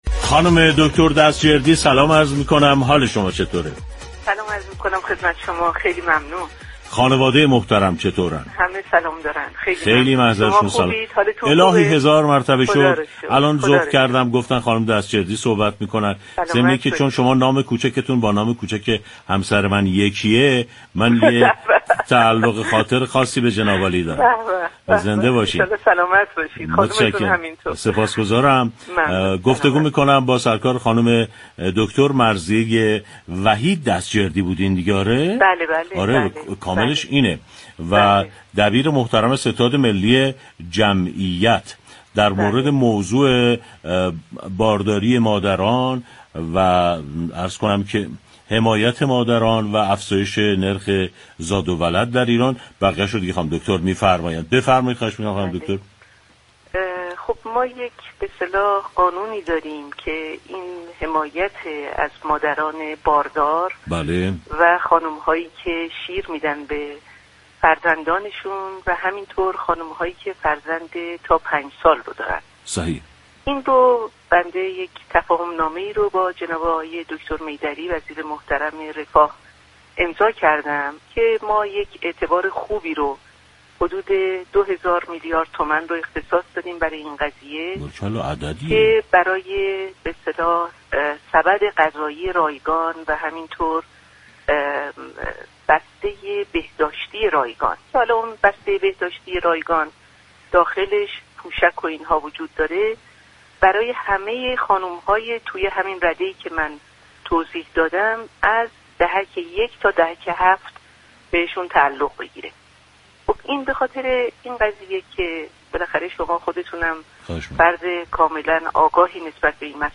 دبیر ستاد ملی جمعیت كشور در گفت‌و‌گو با رادیو تهران از امضای تفاهم نامه ای با وزارت تعاون، كار و رفاه اجتماعی خبر داد و گفت: بر اساس این تفاهم نامه اعتباری حدود 2 هزار میلیارد تومان برای ارائه سبد غذایی و بسته بهداشتی رایگان به زنان باردار، مادران شیرده و مادران دارای فرزند زیر 5 سال در دهك 1 تا 7 در نظر گرفته شده است.